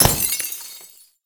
hit_glass.ogg